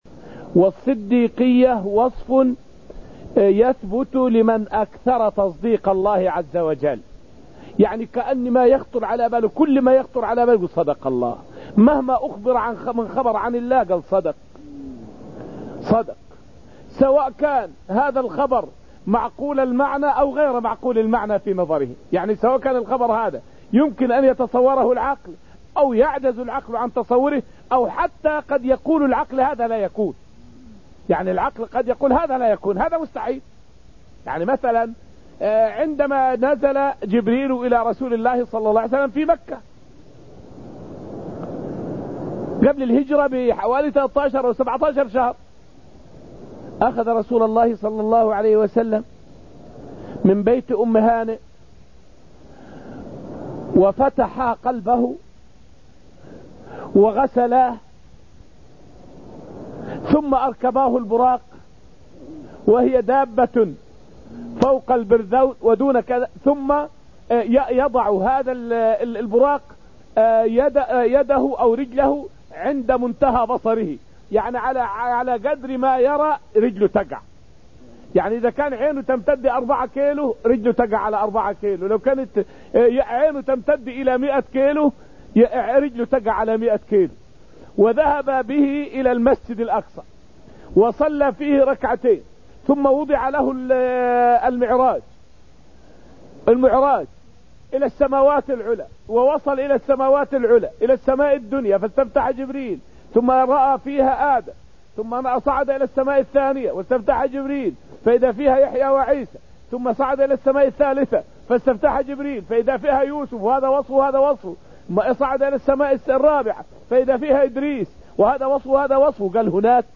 فائدة من الدرس الثامن عشر من دروس تفسير سورة الحديد والتي ألقيت في المسجد النبوي الشريف حول الفرق بين أهل السنة وأهل الأهواء في المرجعية.